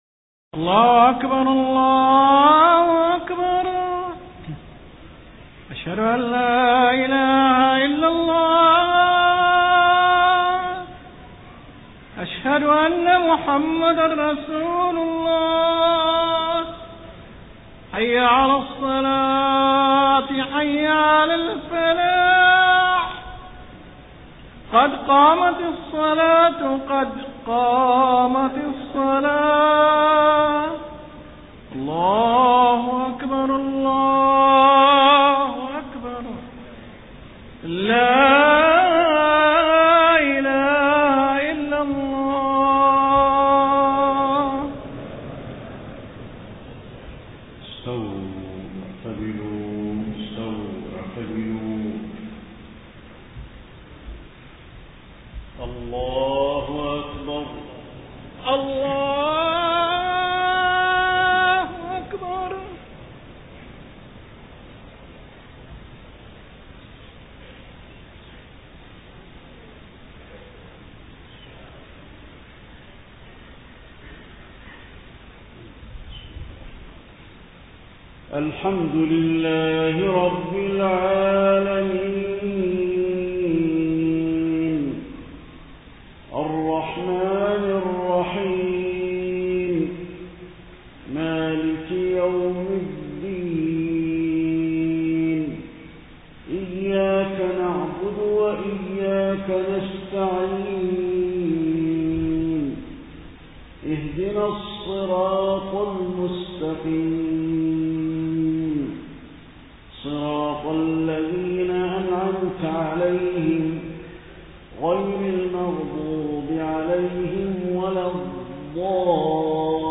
صلاة المغرب 18 صفر 1431هـ خواتيم سورة النحل 120-128 > 1431 🕌 > الفروض - تلاوات الحرمين